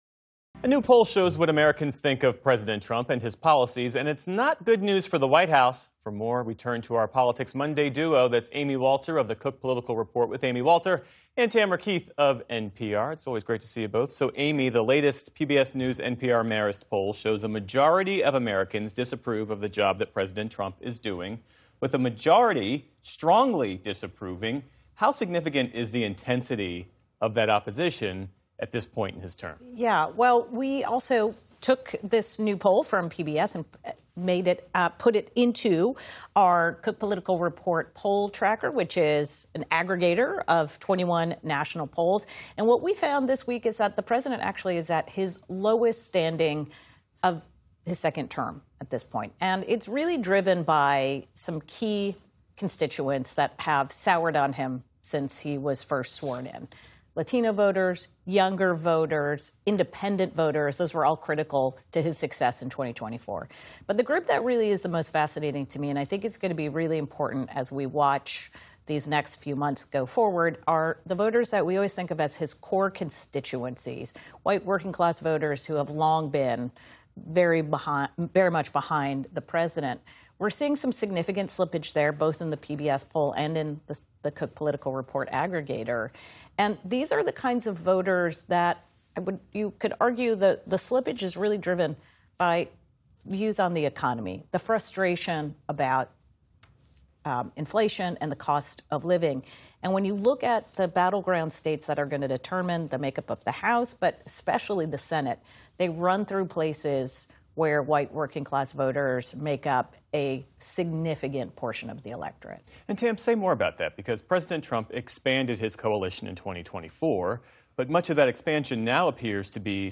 Posted each Monday by 9 p.m., the Politics Monday podcast includes the full audio of every on-air segment.
NPR's Tamara Keith and Amy Walter of the Cook Political Report with Amy Walter join Geoff Bennett to discuss the latest political news, including a new poll showing what Americans think of President Trump and his policies, how it will influence midterm voters and Trump's racist post on social media.